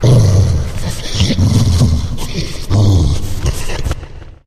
Divergent / mods / Soundscape Overhaul / gamedata / sounds / monsters / lurker / breath_0.ogg
breath_0.ogg